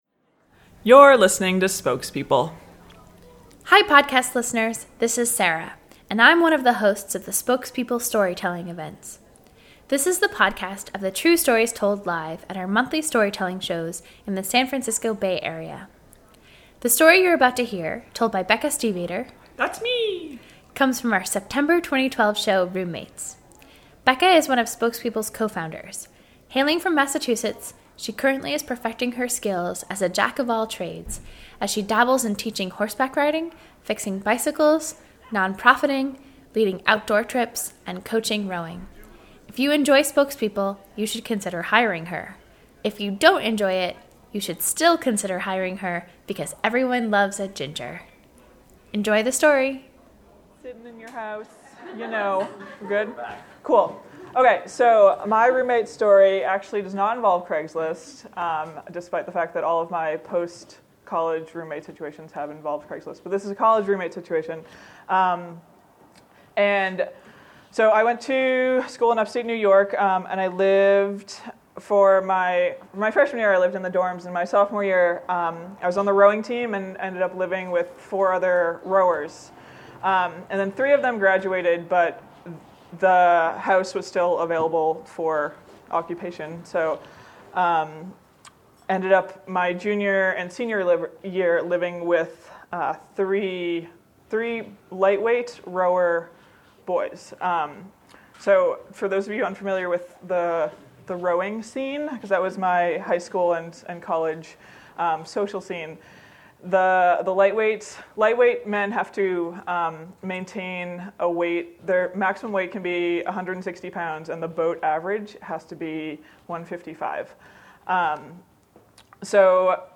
This week we're giving thanks for all of the crazy things past roommates have done to bring us the story for today. And thank you to our wonderful audience who came to hear this story told live in September at the Red Poppy Art House in San Francisco.